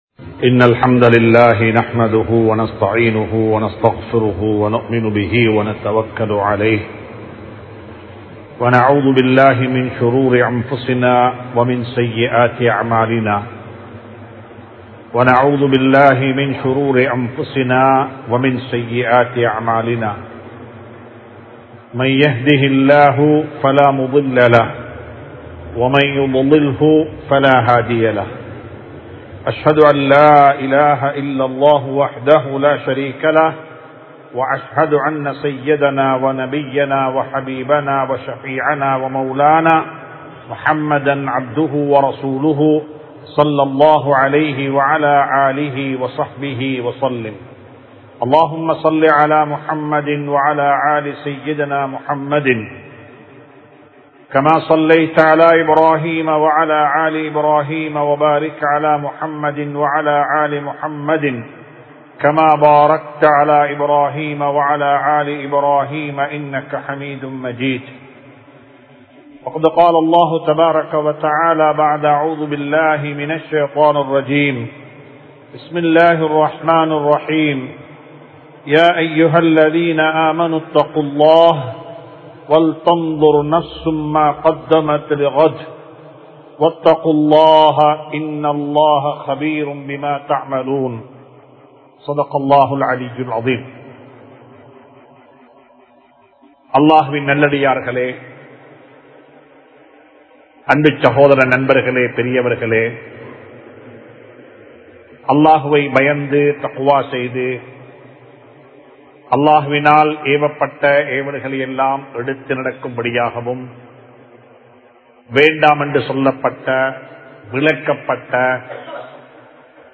மரணத்திற்கு முன்னர் | Audio Bayans | All Ceylon Muslim Youth Community | Addalaichenai